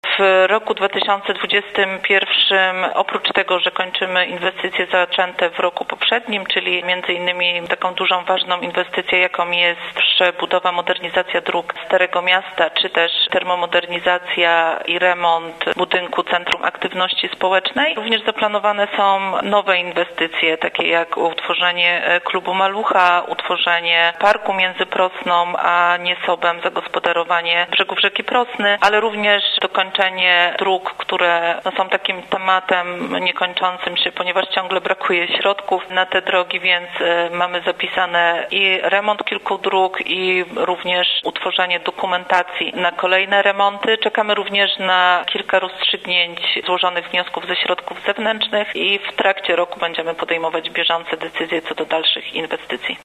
– mówiła wiceburmistrz Wieruszowa, Marta Siubijak.